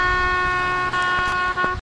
街头音乐家吹喇叭用DS40录制，因为左边的麦克风神秘地停止了工作，在Wavosaur中被抢救成了单声道录音。